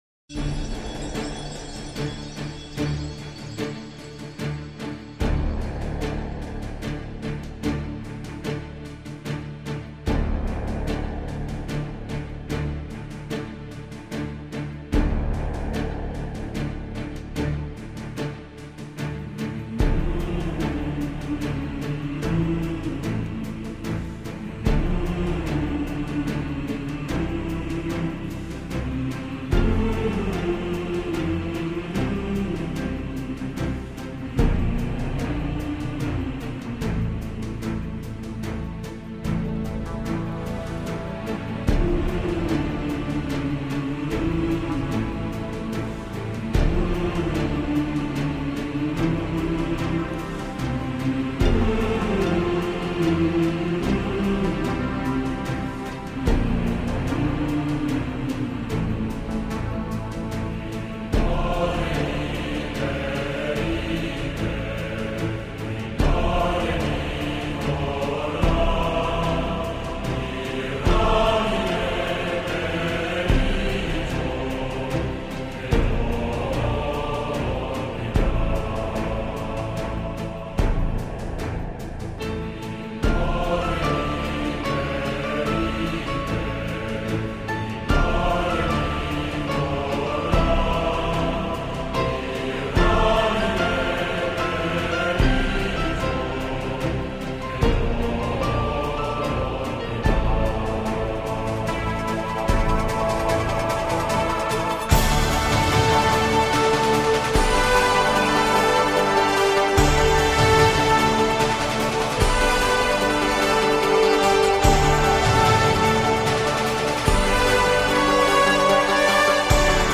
他的许多史诗般的电子音乐作品和电影配乐深得乐迷们的钟爱